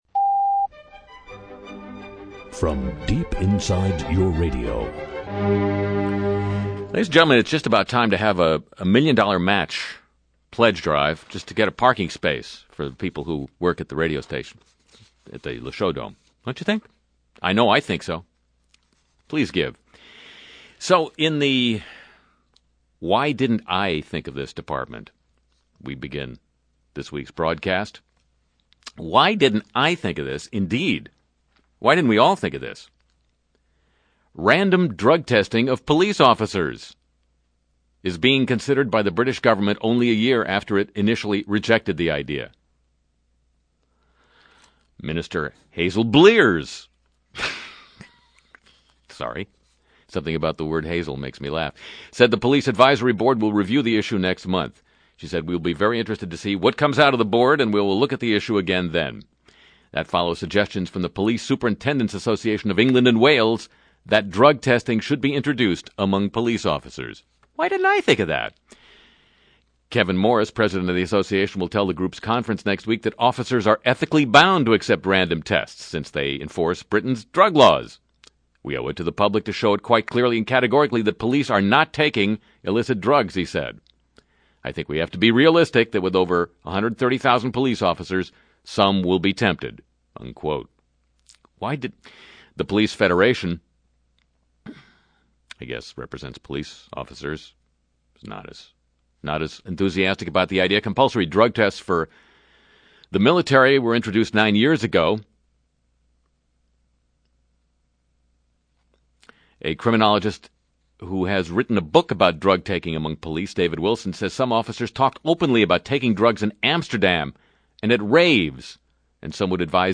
Music & Segments